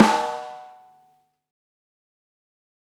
Snare beta98:AmpC 8.wav